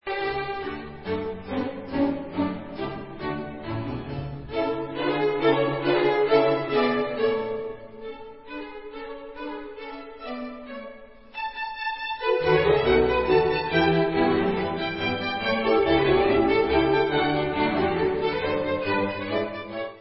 housle
Koncert pro housle a orchestr D dur:
Allegro ma non molto